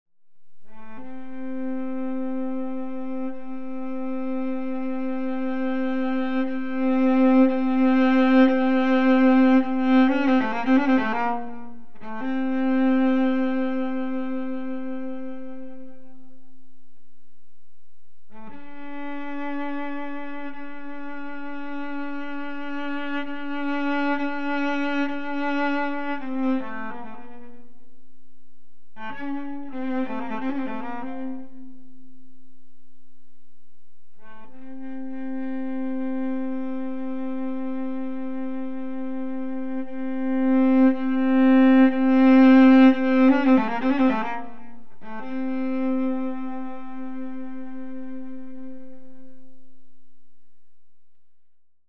Suite in One Movement for Solo Cello